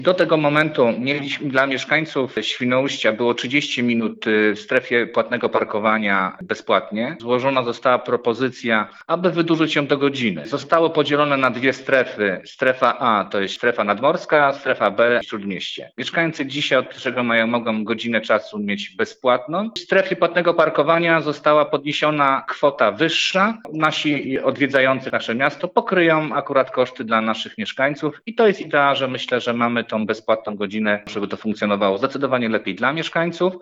Opowiada Radny Sławomir Nowicki z Grupy Morskiej – Cała Naprzód.